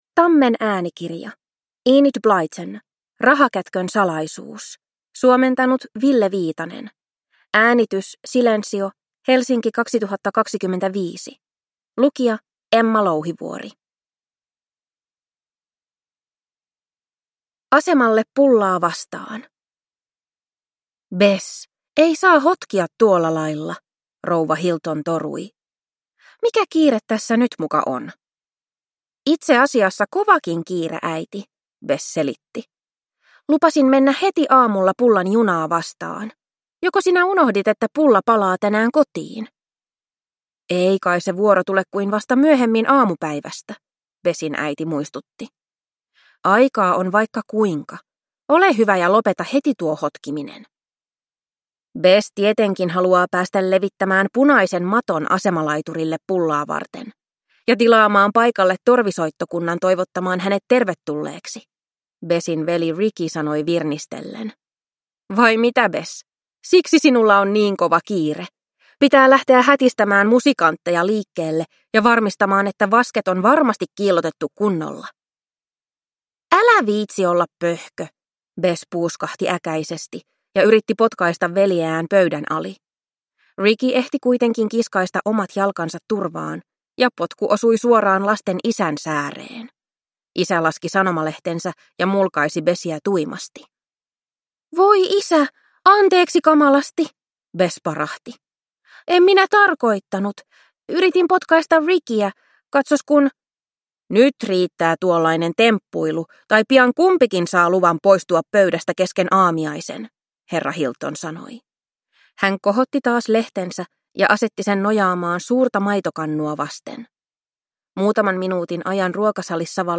Rahakätkön salaisuus – Ljudbok